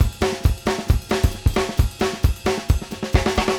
CYM GROOVE-L.wav